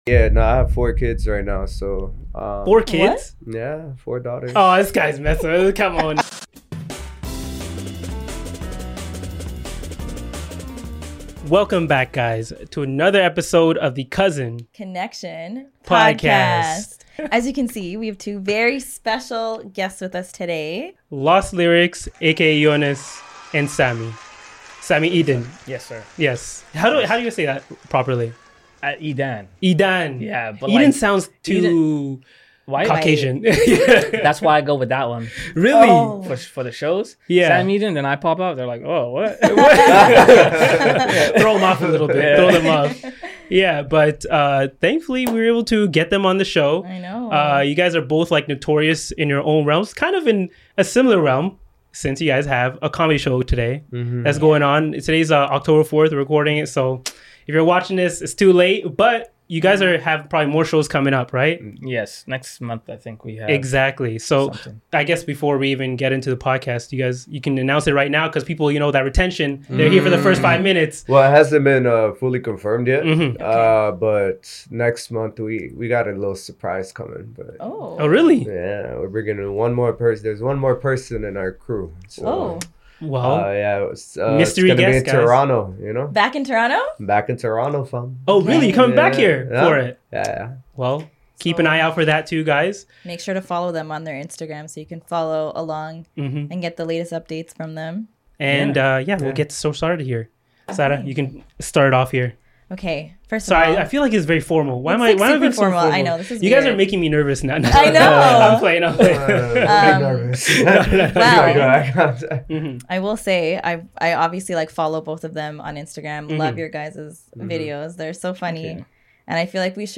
They both flew to Toronto for their show last weekend and we were fortunate enough to sit down with them and have a really good conversation about their start in comedy, their shift from skits to stand-up, how faith has played a role in their lives and so much more!